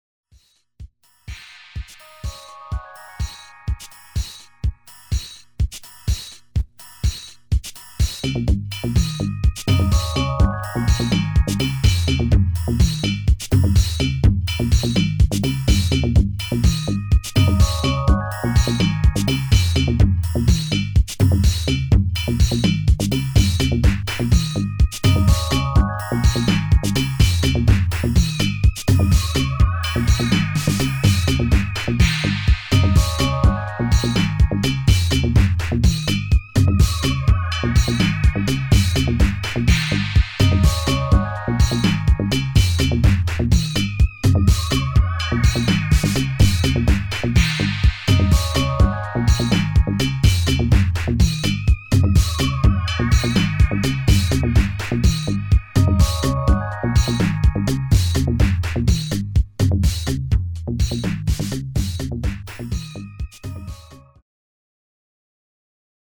アーリー・シカゴ・ハウス・スタイルのディープ・ハウス・チューンを5曲収録！